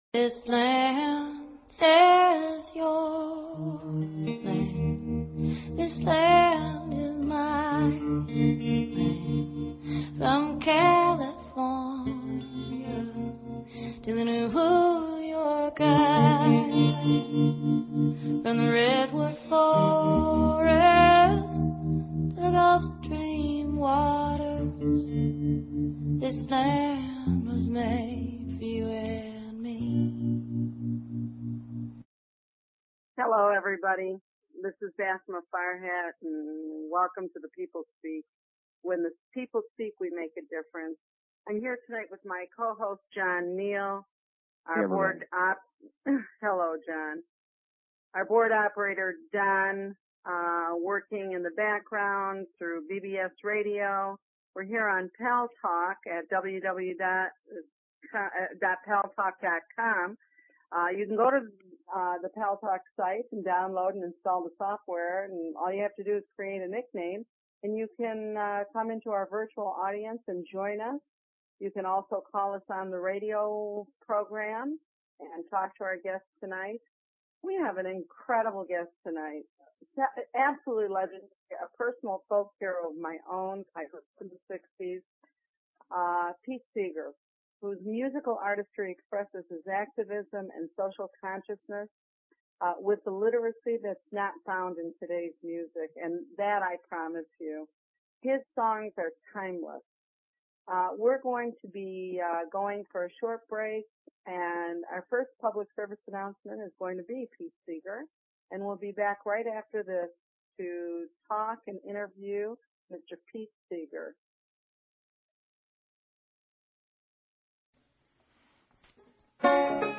Talk Show Episode, Audio Podcast, The_People_Speak and Pete Seeger on , show guests , about , categorized as Arts,Education,Music,Philosophy,Politics & Government
Guest, Pete Seeger